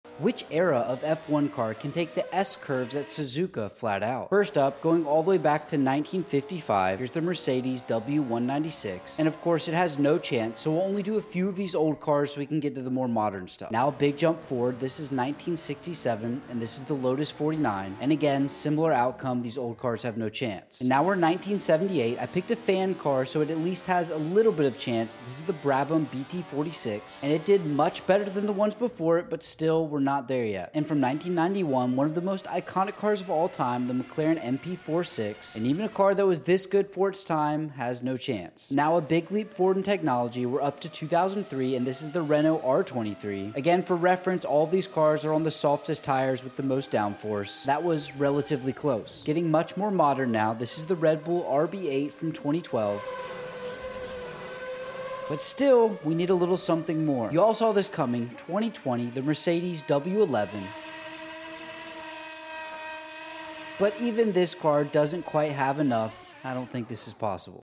F1 Eras vs. “S” Curves sound effects free download